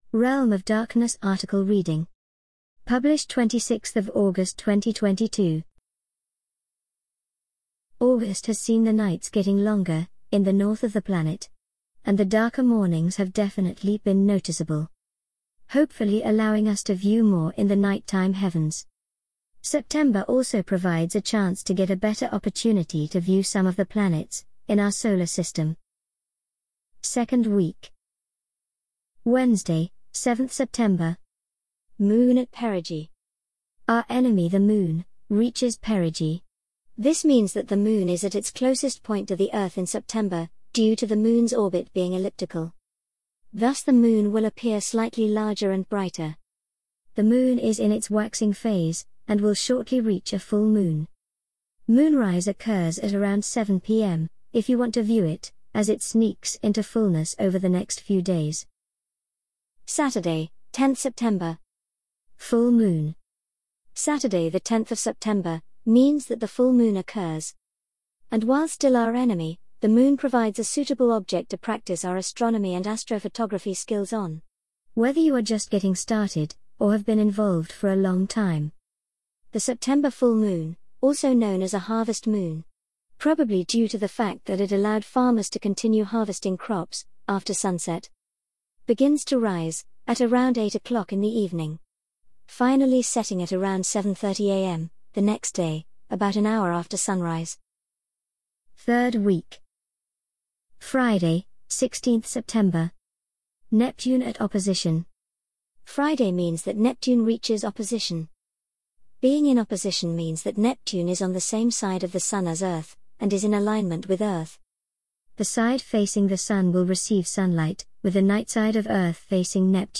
An audio reading of the Realm of Darkness September 2022 Article